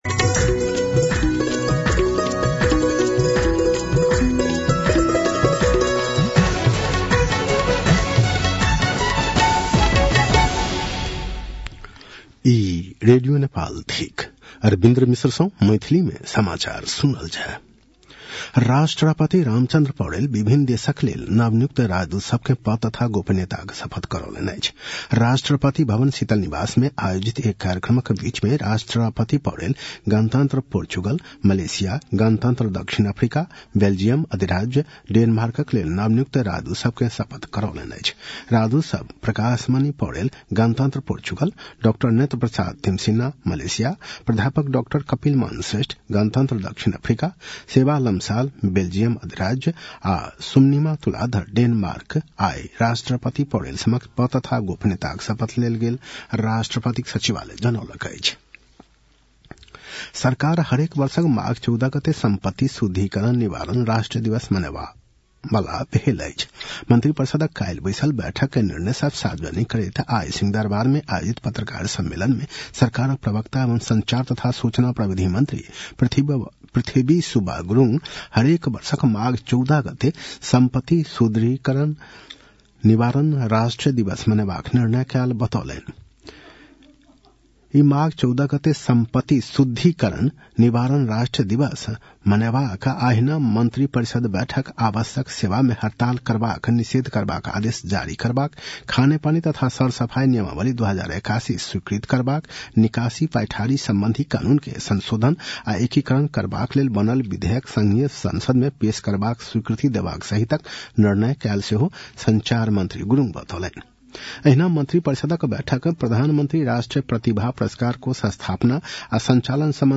An online outlet of Nepal's national radio broadcaster
मैथिली भाषामा समाचार : १८ पुष , २०८१